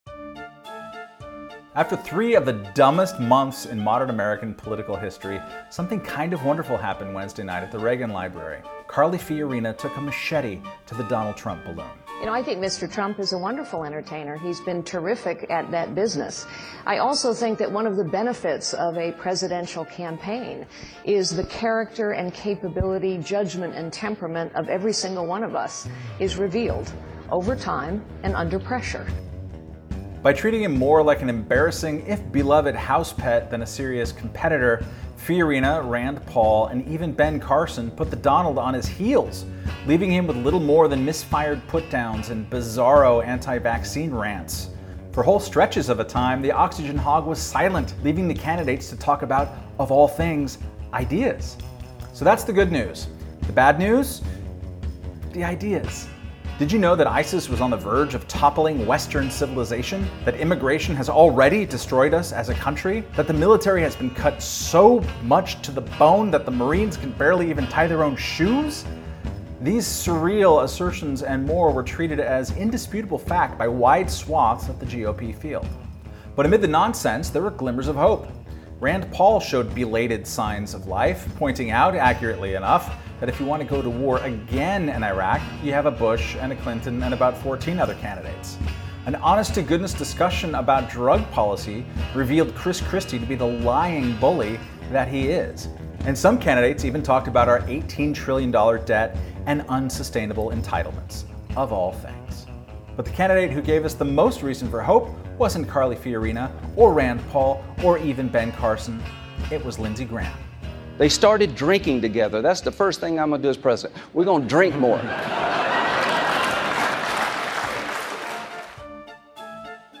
Music: "Carny's Dance "by Kevin MacLeod (Creative Commons) and "Bronco Romp" by Waylon Thornton (Creative Commons).